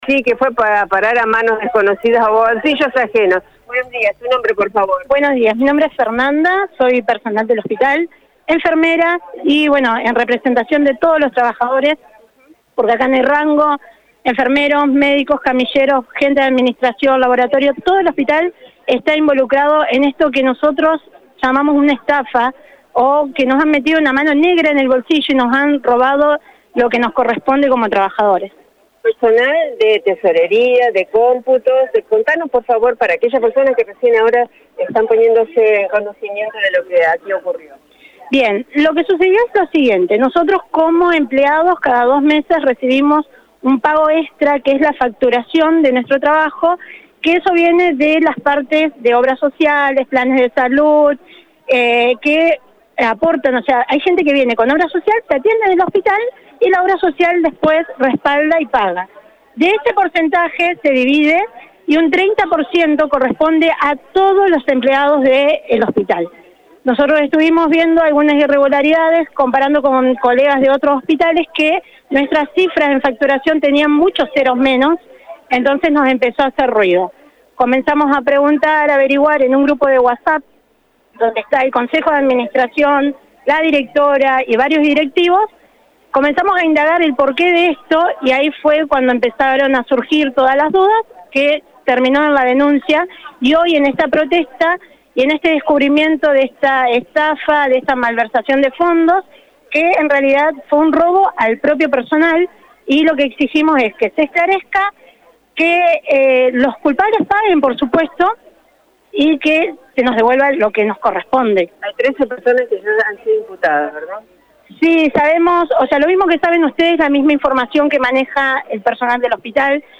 Un nutrido grupo de trabajadores del Hospital Iturraspe protestó este viernes por los millones de pesos que derivaron a cuentas de terceros.